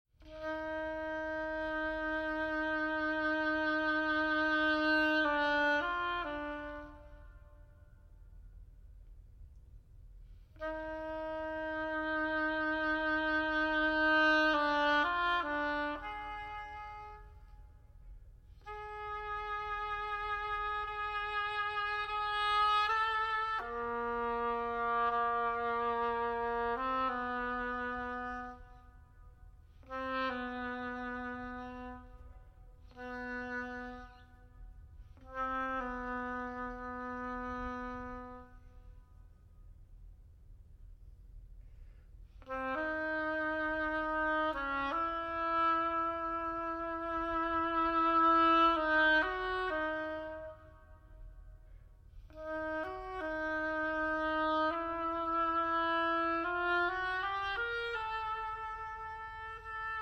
Piano
Oboe